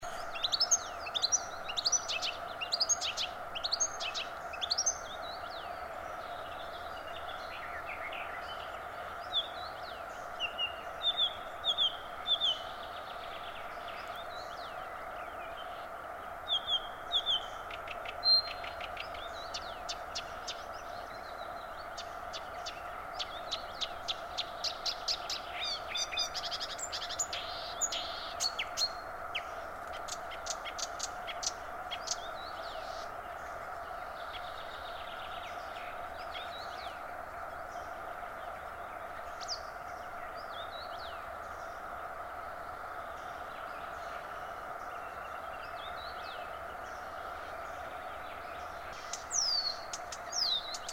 Imitations in recorded song of hybrid Marsh x Blyth's Reed Warbler Acrocephalus palustris x dumetorum, Espoo, Finland, 19 June 2003
0:09 loud 'tju-tju, tji-tji, tju-tju' etc. sounds like some turdid song, possibly Song Trush Turdus philomelos
0:19 soft 'tchep-tchep-tchep' calls of Scarlet-chested Sunbird Nectarinia senegalensis
0:49 long thin descending whistle of Eurasian Penduline Tit Remiz pendulinus - but could be borrowed from a dumetorum imitating a Remiz etc, this kind of whistles is very typical of Blyth' s Reed Warbler song, the whistles are alternating with 'tec-tec', and 'wu-wu-wu'